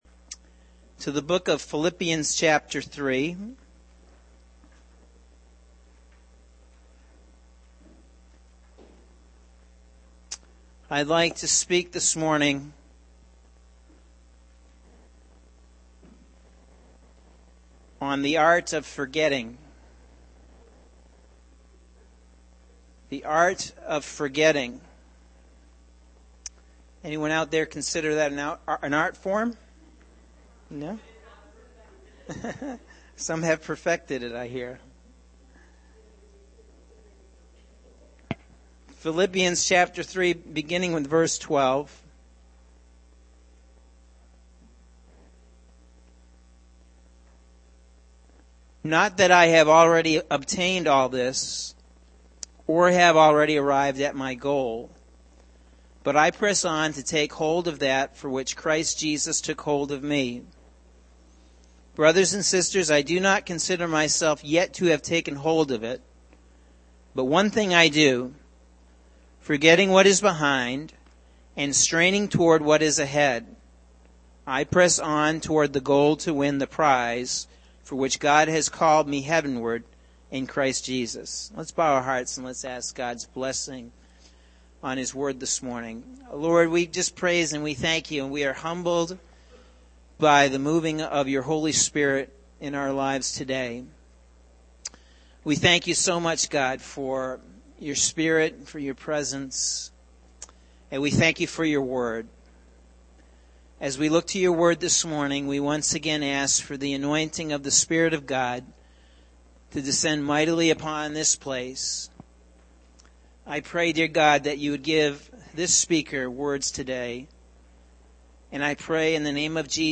Sunday June 5th – AM Sermon – Norwich Assembly of God